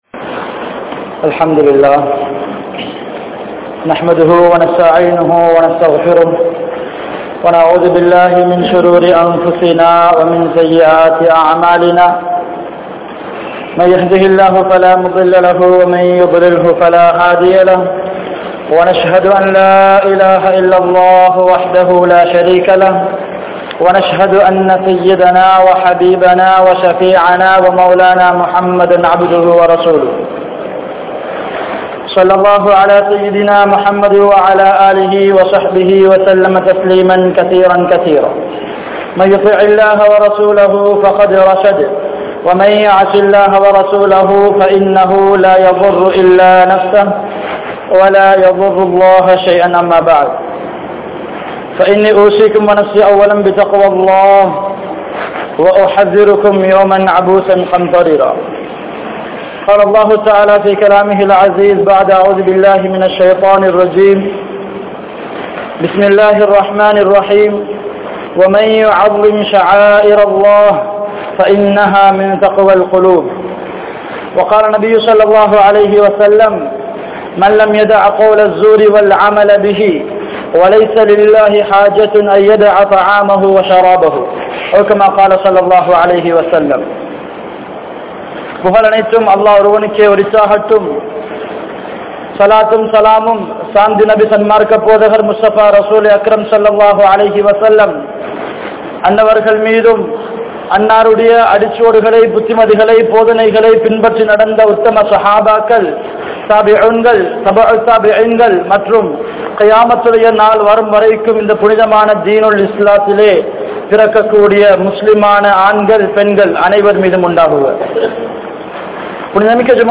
Ramalaanai Ganniya Paduthuvoam (ரமழானை கண்ணிய படுத்துவோம்) | Audio Bayans | All Ceylon Muslim Youth Community | Addalaichenai